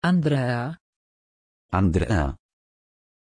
Pronuncia di Andreea
pronunciation-andreea-pl.mp3